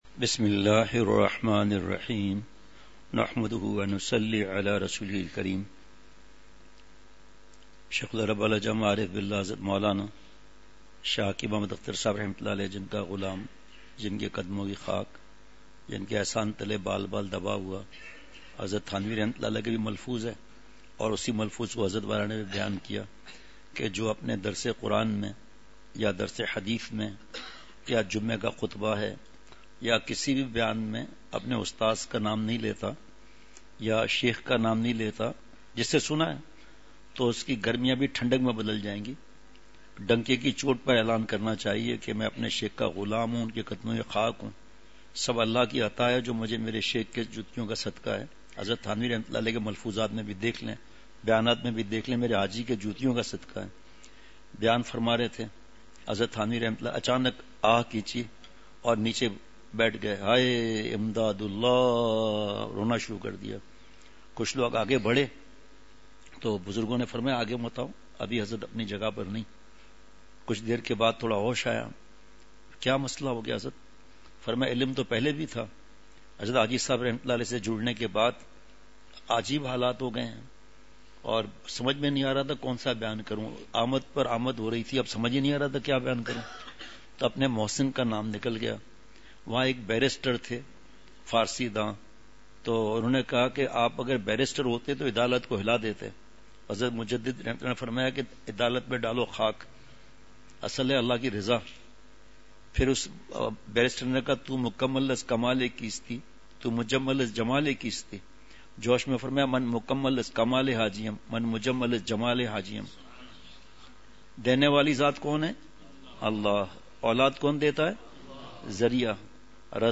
عشاء مجلس ۲۹ جنوری ۲۶ء:صحبتِ صالحین !
*مقام:مسجد اختر نزد سندھ بلوچ سوسائٹی گلستانِ جوہر کراچی*